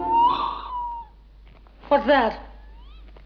It sounds like an exotic bird or something, but is it meant to be the voice of the alien?
strange-sound.wav